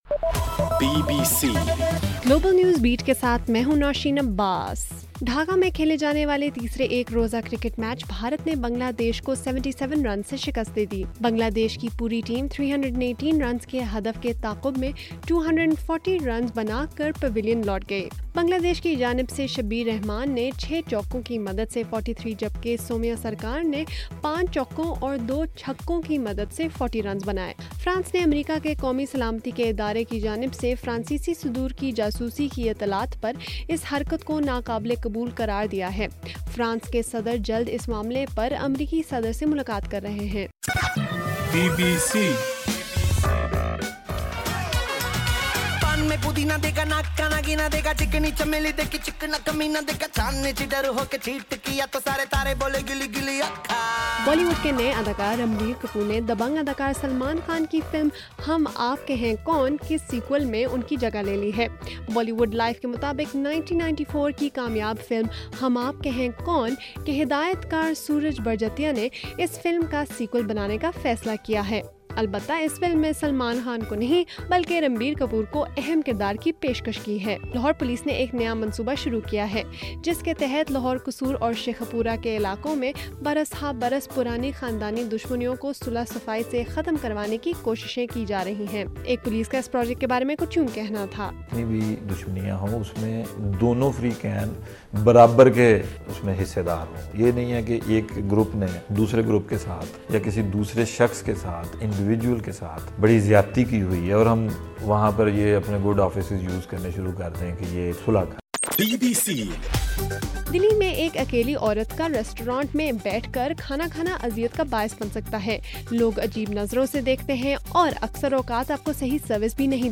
جون 24: رات 12 بجے کا گلوبل نیوز بیٹ بُلیٹن